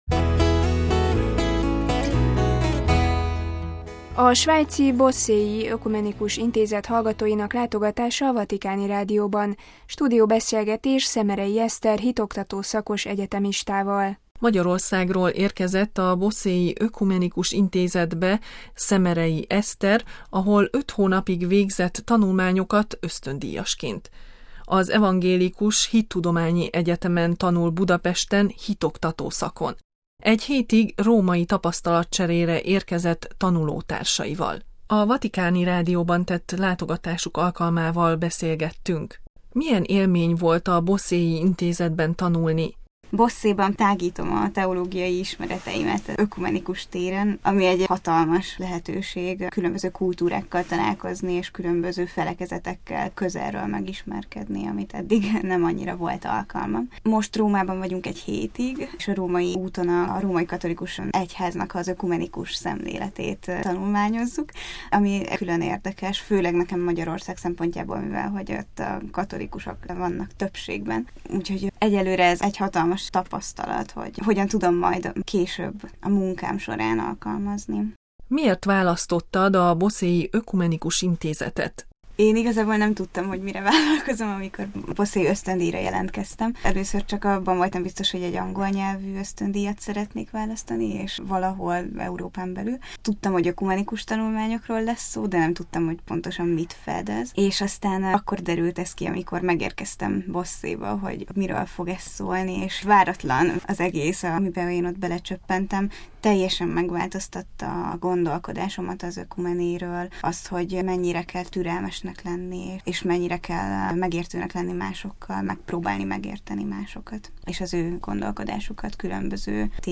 stúdióbeszélgetés